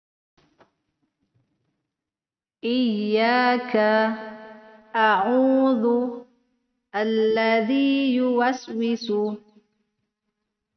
a. Mad Ashli / Mad Thobi’i
Standar 2 harokat itu adalah 1x ayun suara atau dua ketukan sesuai detik jarum jam.